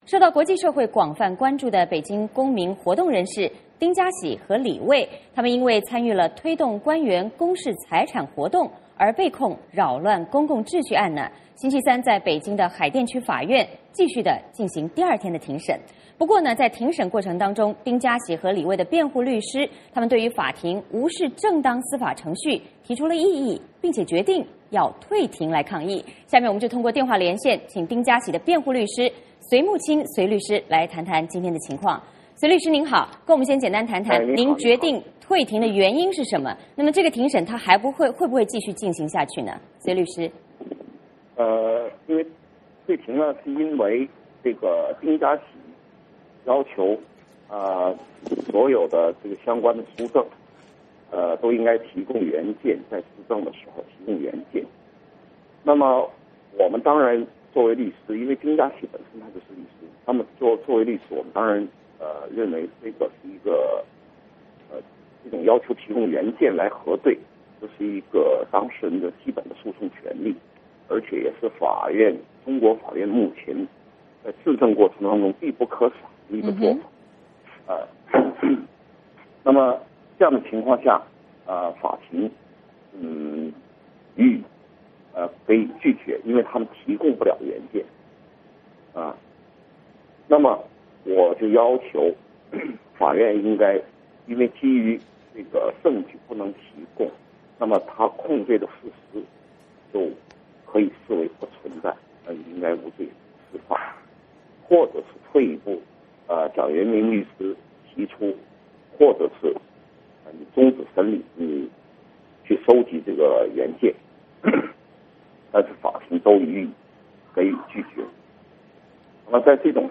下面我们通过电话连线